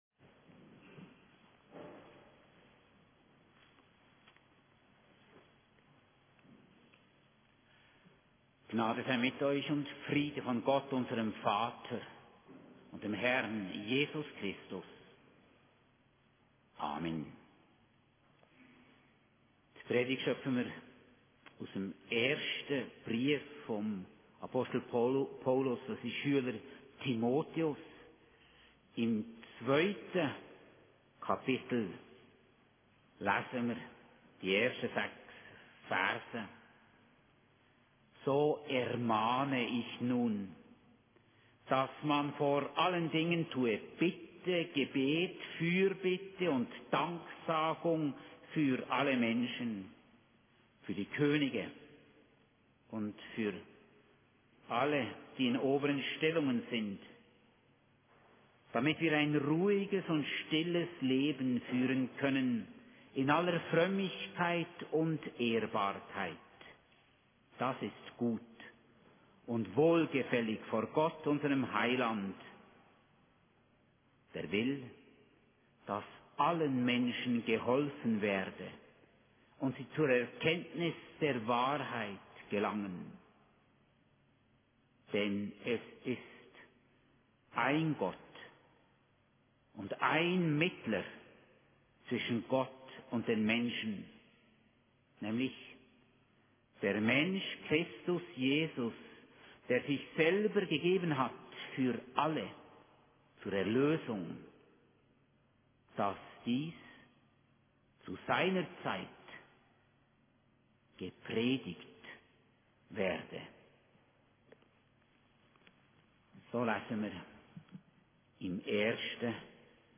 Predigt aus 1. Timotheus 2,1-6 im Abendmahlsgottesdienst am 2.November 2025 in Hundwil AR Eingangswort Hiob 33,26, Lesung Markus 2,1-12